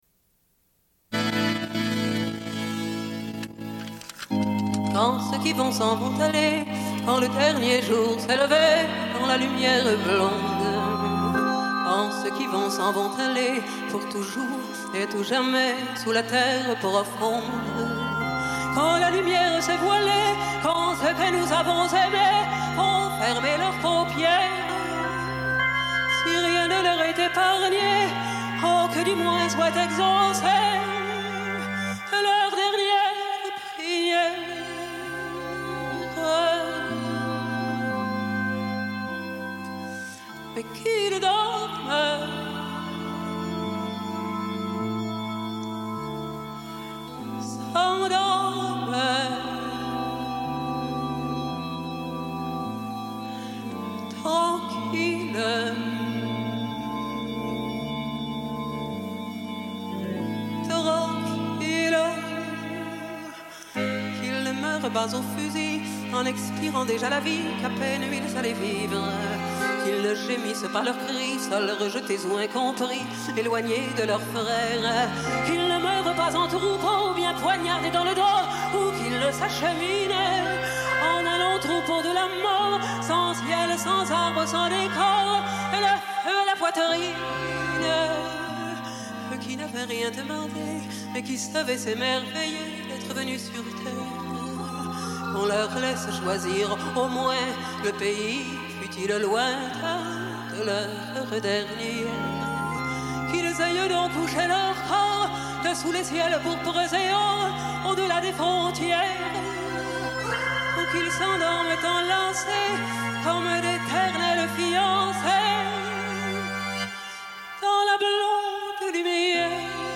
Une cassette audio, face A31:08